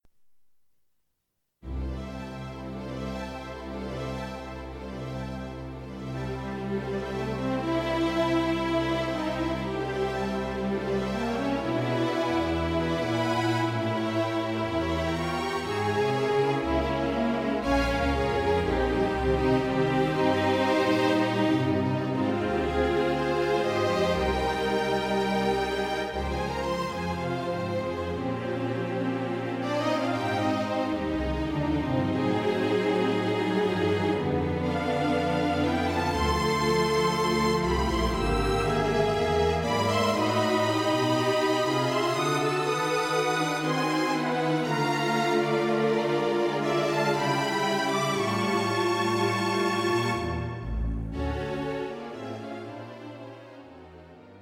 String Quintet